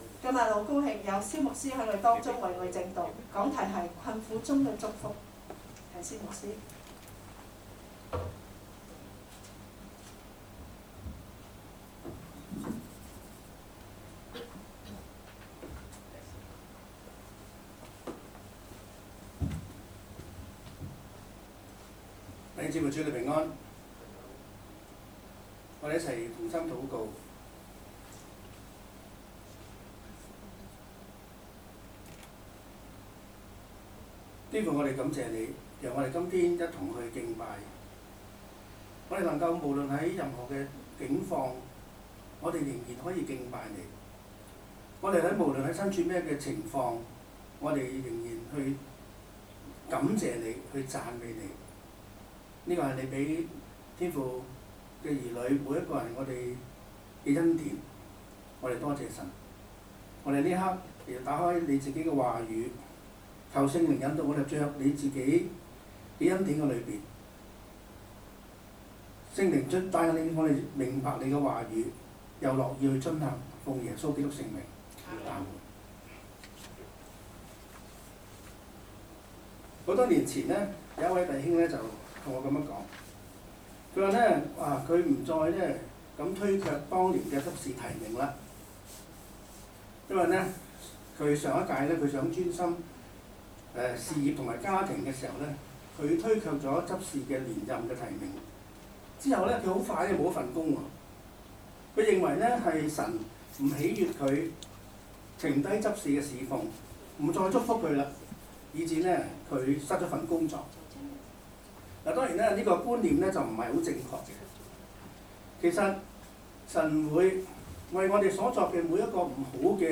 2020年2月16日崇拜
2020年2月16日講道